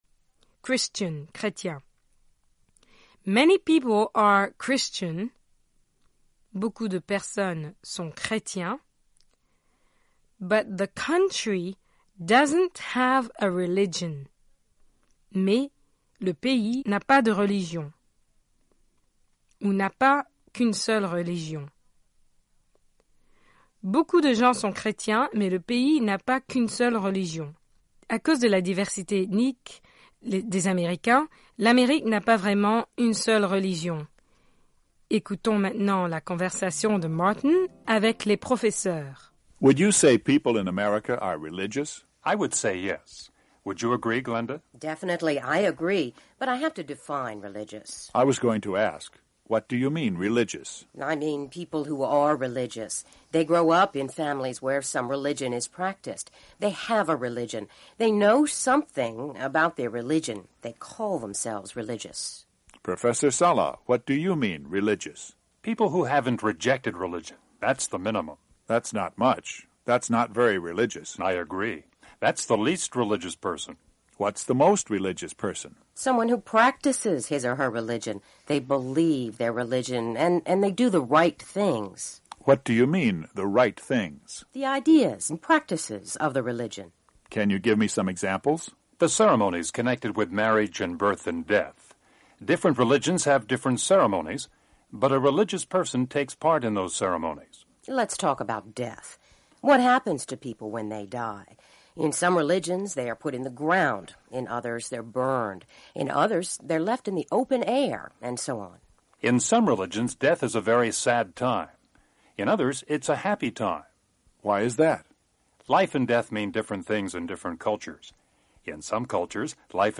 RCA: Reportage Special